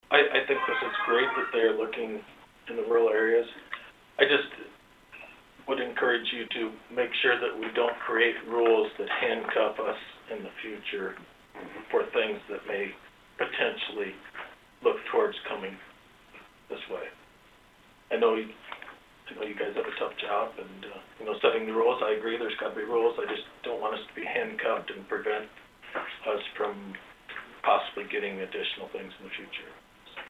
(Adair Co) The Adair County Board of Supervisors held another public hearing this (Wednesday) morning on a proposed ordinance regulating the placement of large scale commercial and industrial buildings.